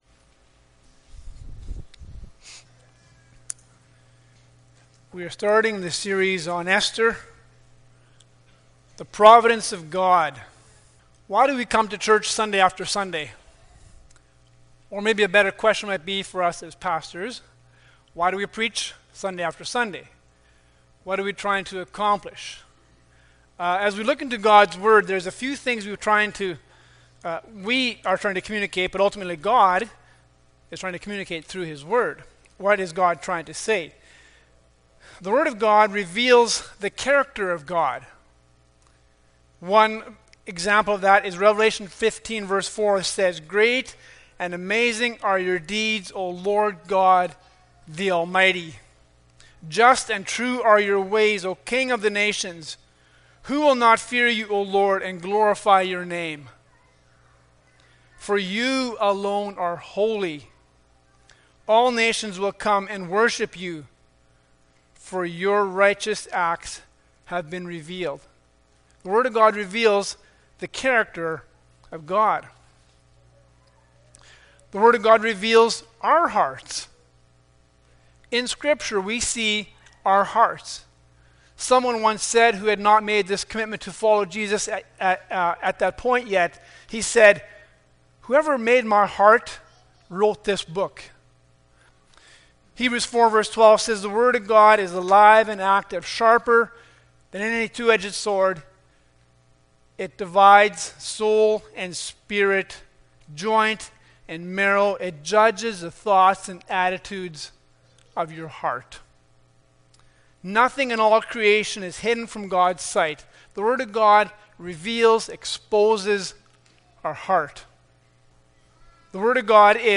Esther 1:1-12 Service Type: Sunday Morning Bible Text